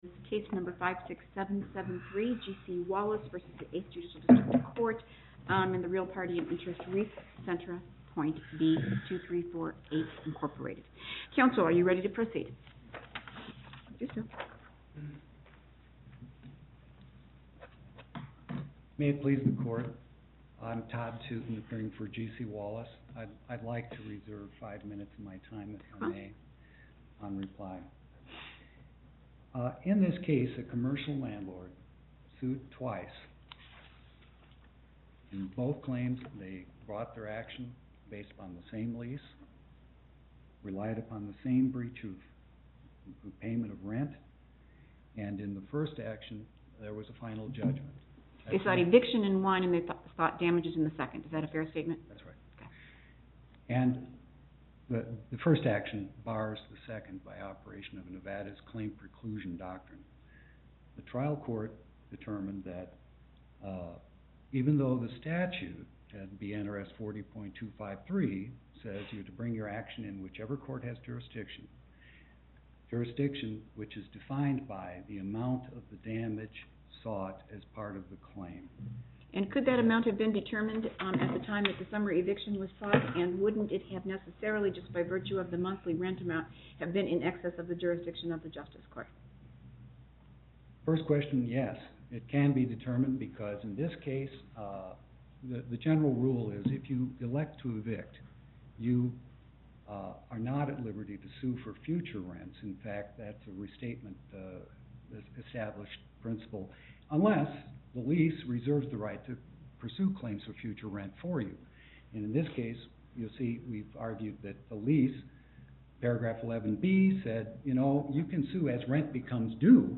Location: Las Vegas Before the Southern Panel, Justice Saitta Presiding
as counsel for the Petitioner